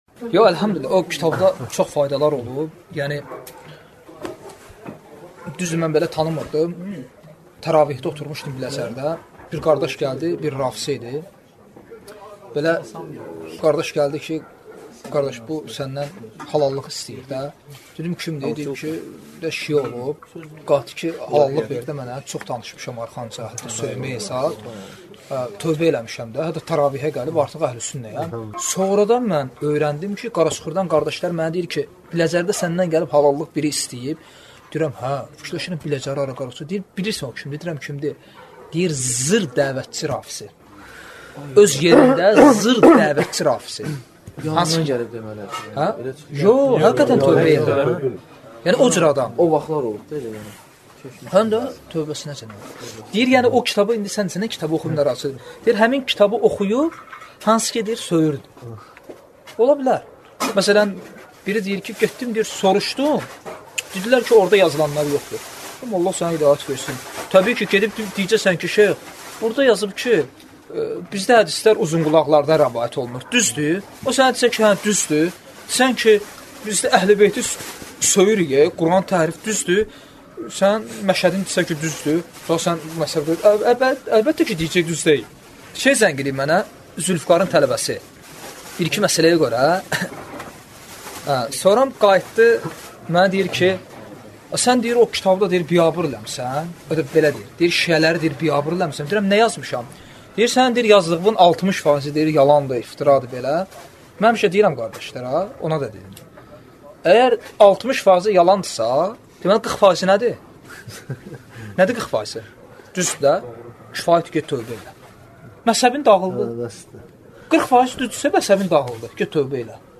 Dərslərdən alıntılar – 86 parça